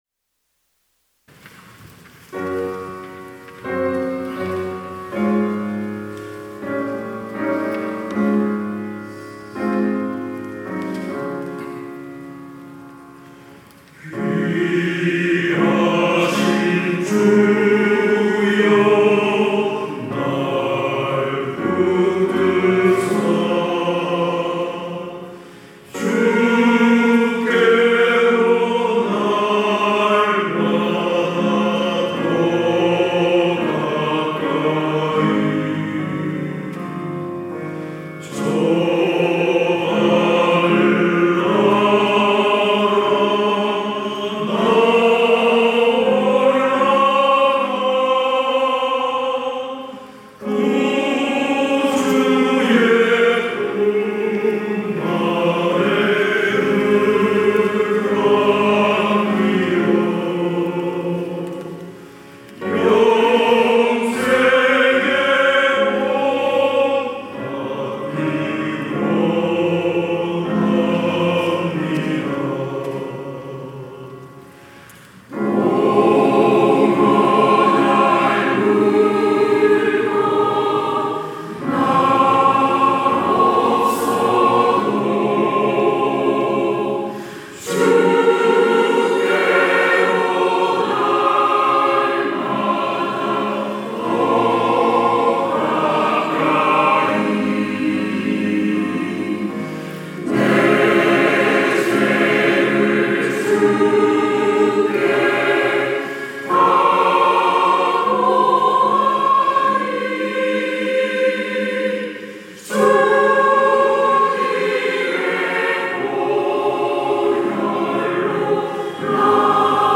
찬양대 가브리엘